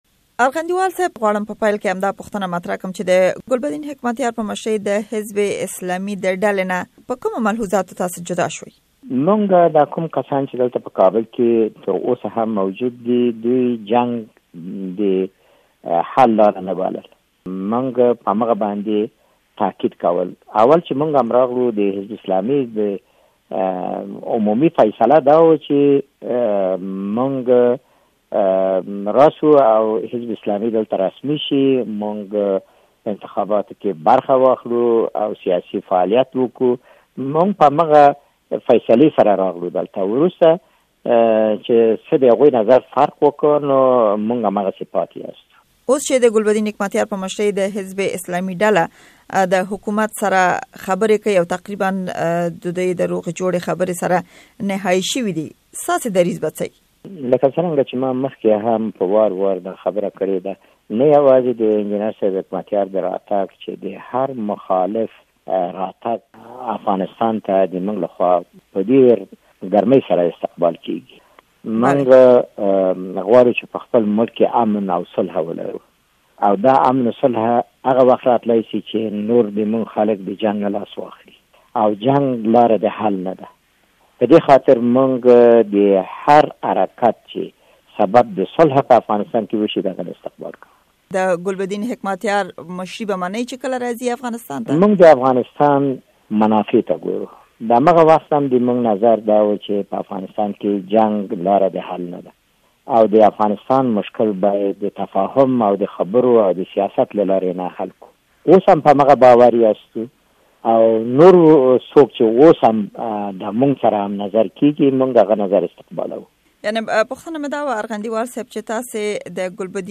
مرکې
عبدالهادي ارغنديوال سره مرکه دلته واورئ